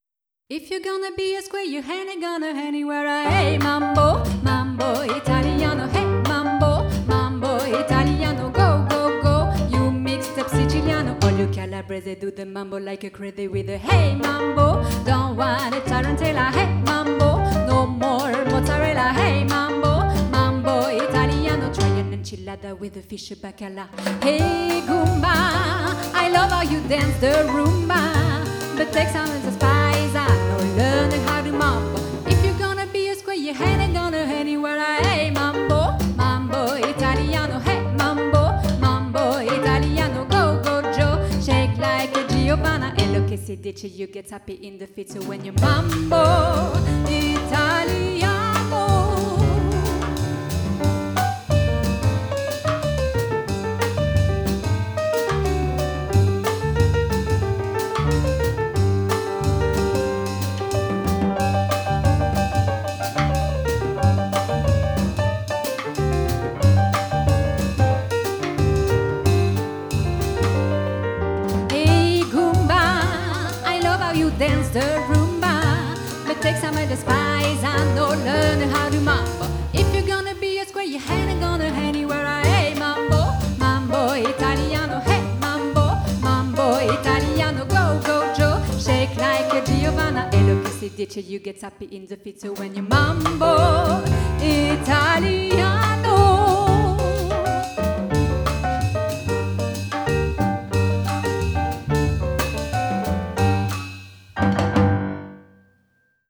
Groupe Funk, Pop, Rock, Jazz